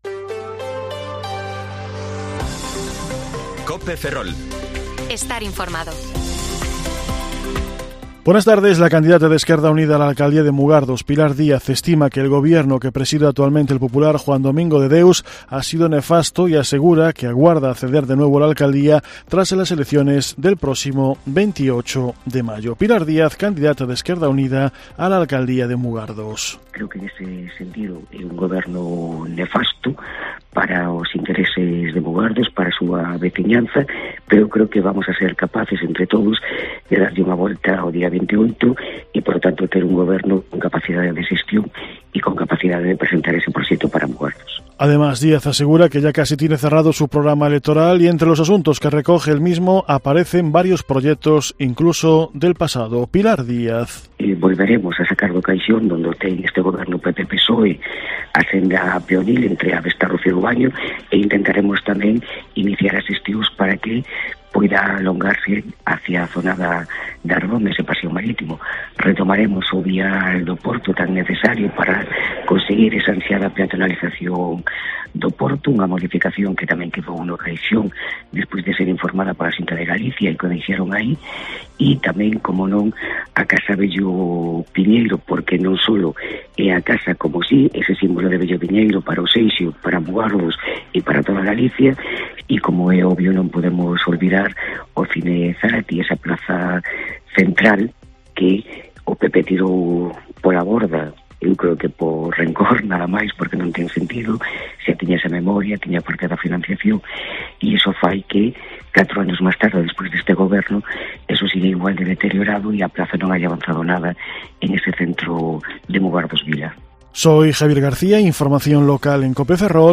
Informativo Mediodía COPE Ferrol 24/4/2023 (De 14,20 a 14,30 horas)